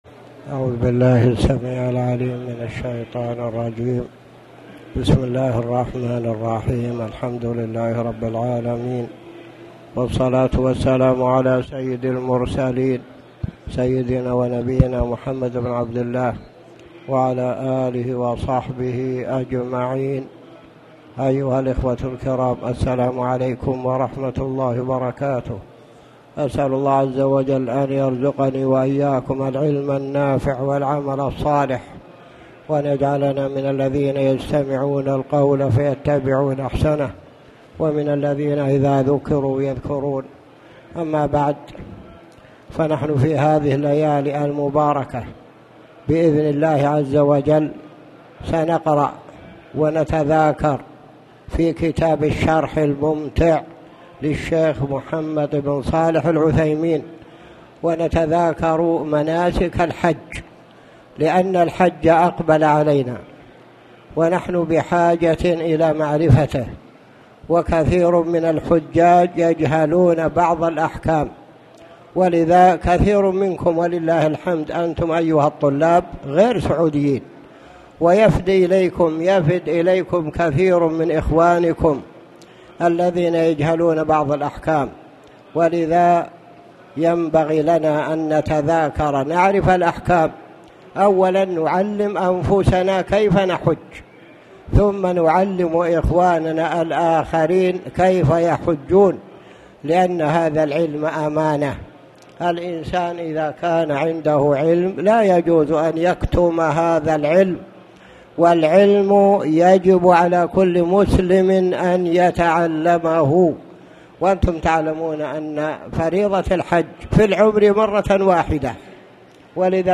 تاريخ النشر ٢٨ شوال ١٤٣٨ هـ المكان: المسجد الحرام الشيخ